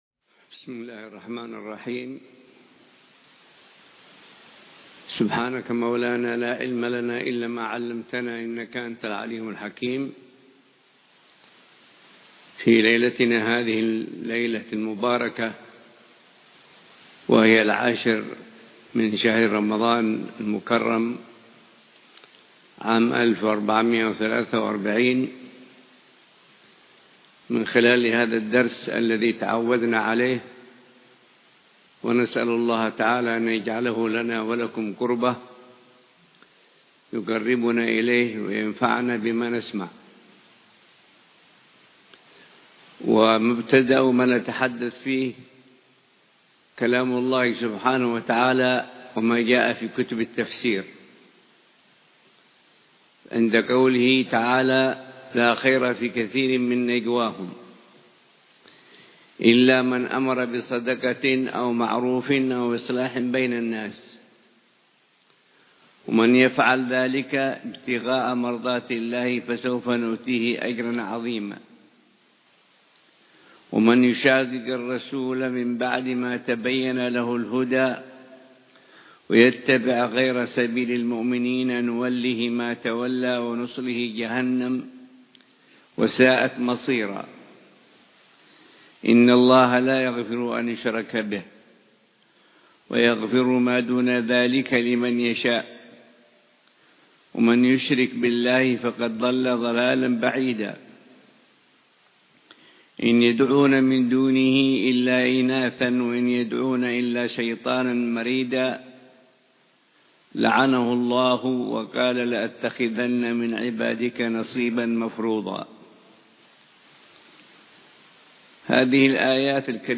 بمدينة أحور